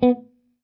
b_note.wav